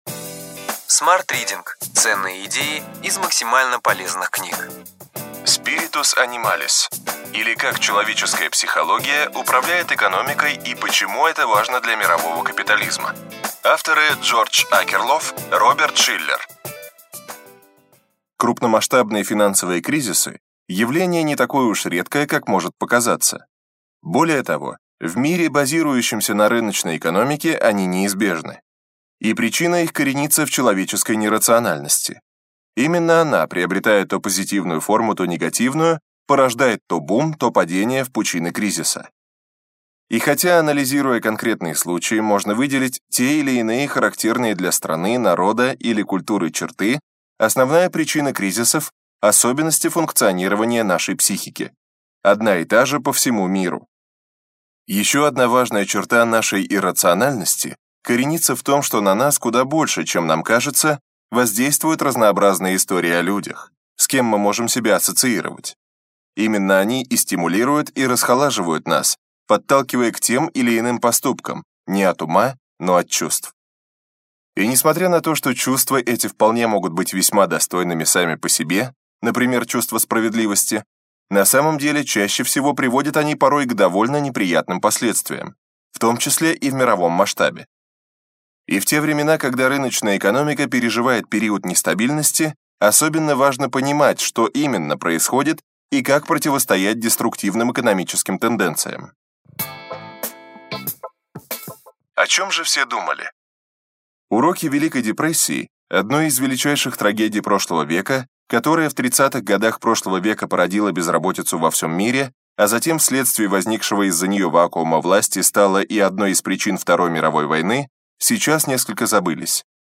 Аудиокнига Ключевые идеи книги: Spiritus Animalis, или Как человеческая психология управляет экономикой и почему это важно для мирового капитализма.